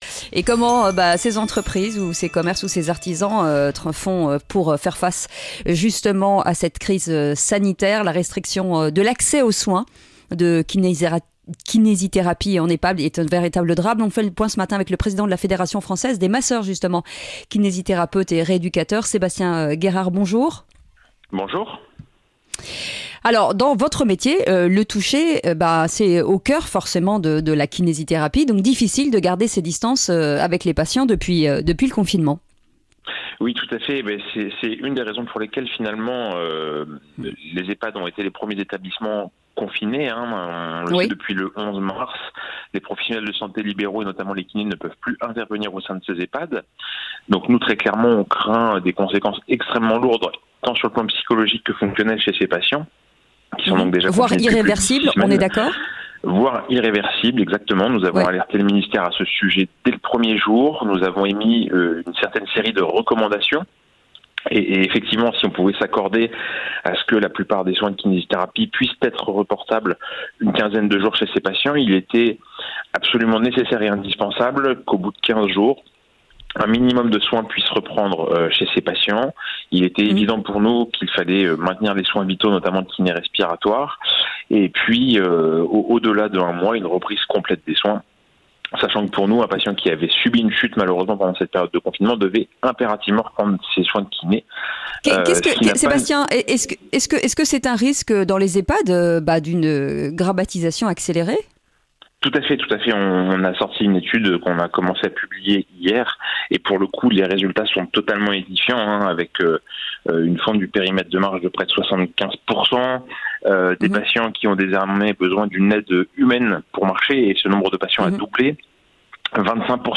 Sud Radio à votre service dans Le Grand Matin Sud Radio à 6h50 avec FIDUCIAL.